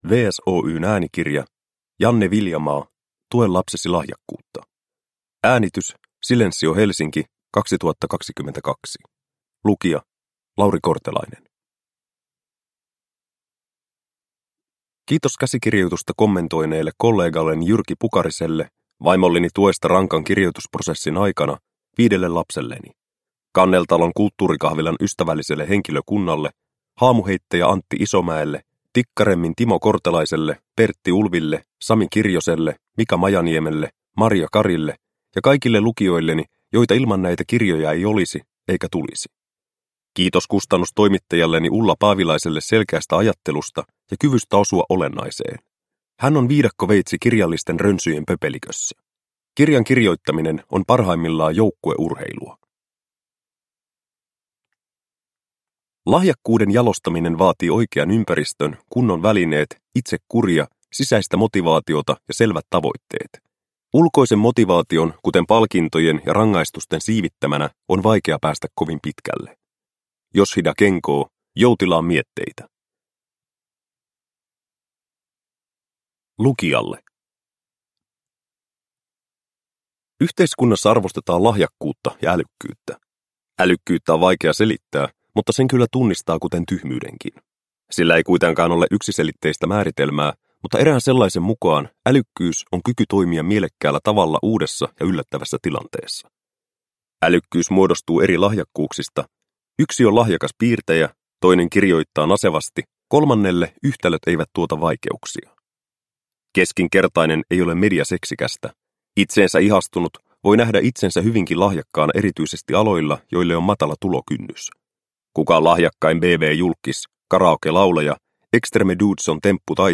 Tue lapsesi lahjakkuutta – Ljudbok – Laddas ner